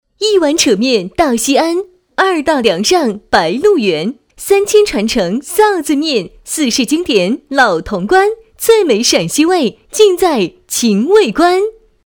女3号
秦味观(节奏感)